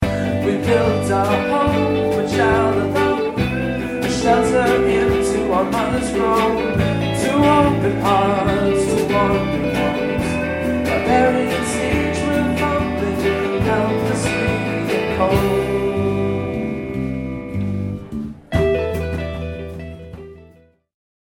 Live at the Revolution Cafe